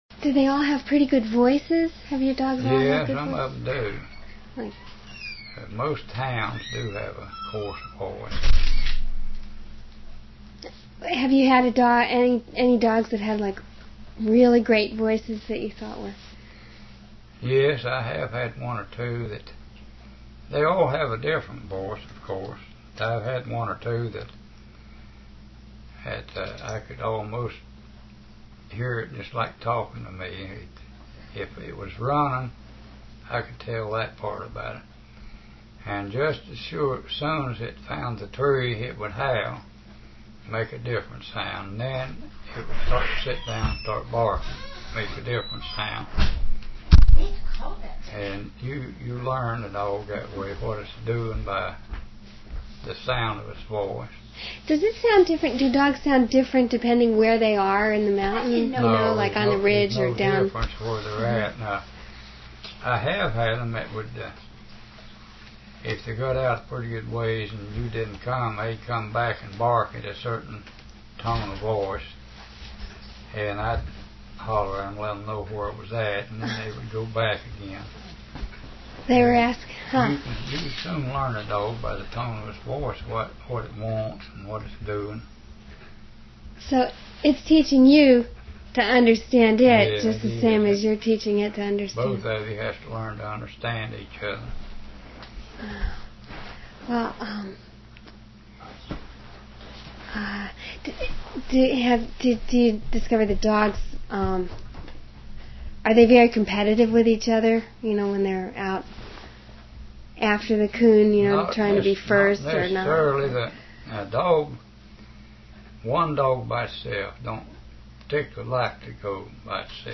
American Houndsmen - Coon Hunting Interview
Archive of Folk Culture, American Folklife Center, Library of Congress